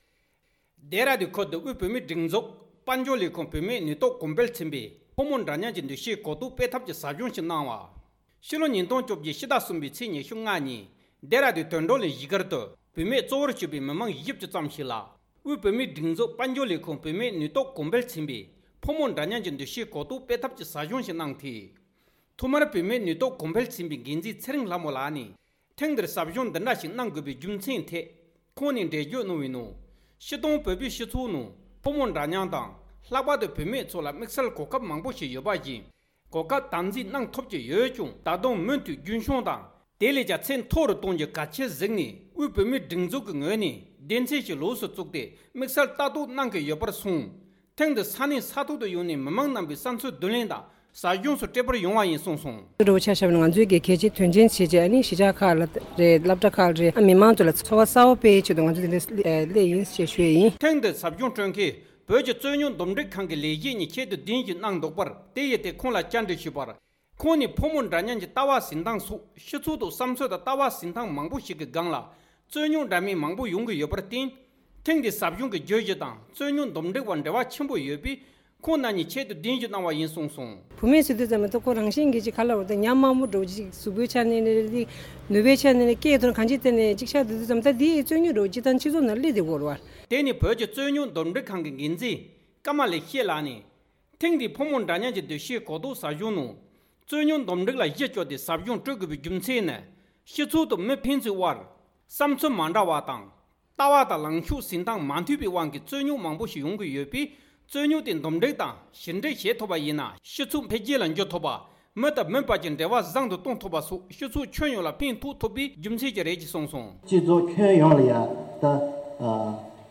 ས་གནས་ནས་བཏང་བའི་གནས་ཚུལ་ལ་གསན་རོགས༎